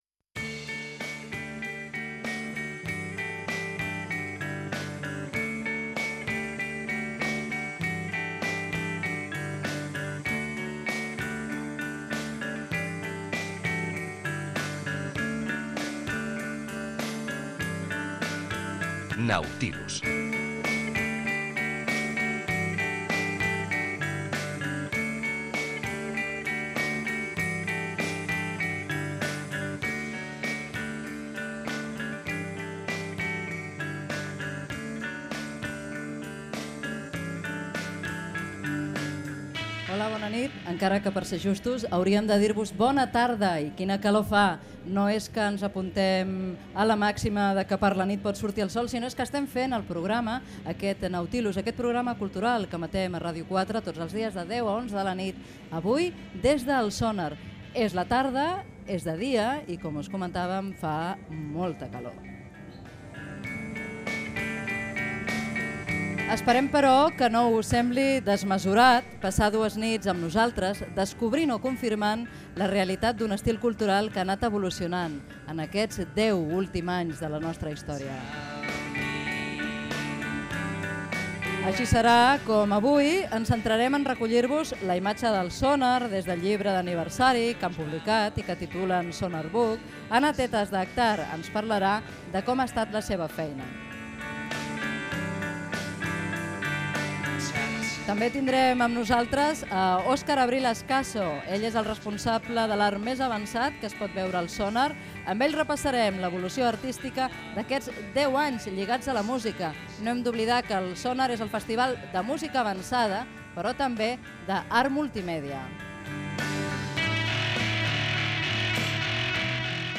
Espai fet des del festival Sónar.